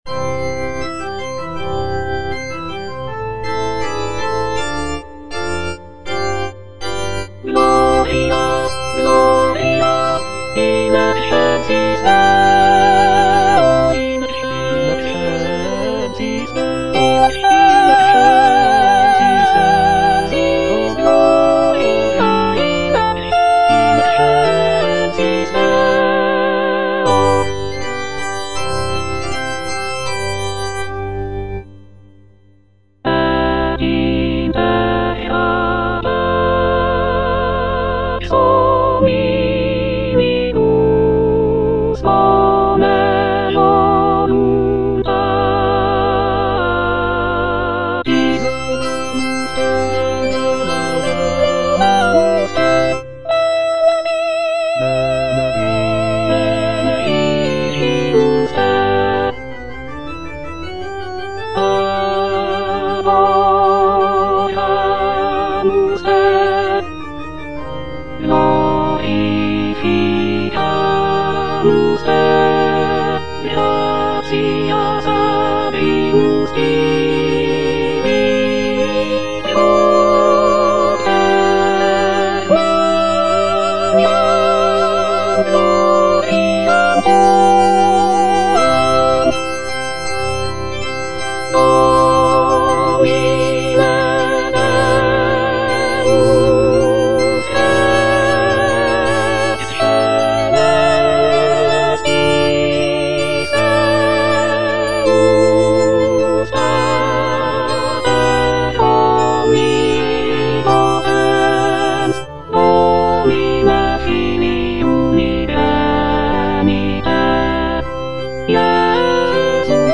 Soprano (Emphasised voice and other voices) Ads stop
sacred choral work